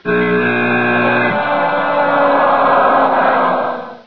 (Side note: I’d like it if TV broadcasts added sound effects to disastrous plays.
ccbuzzer.wav